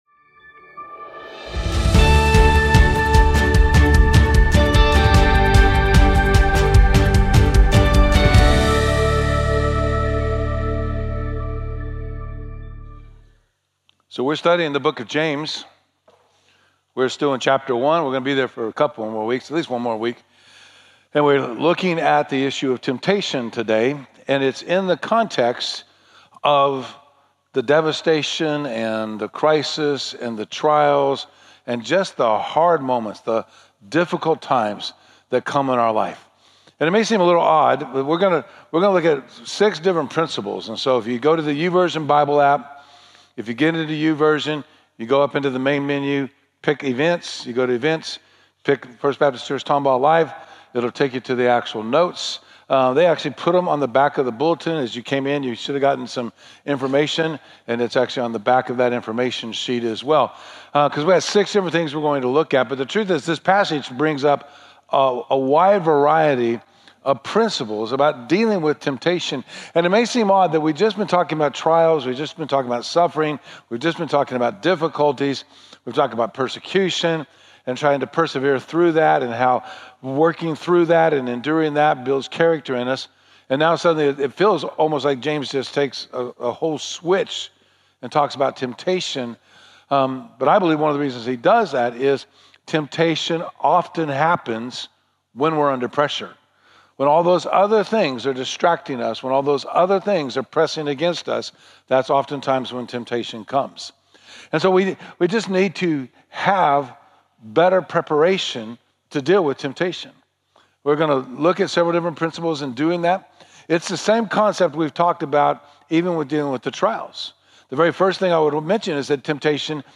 feb-24-sermon.mp3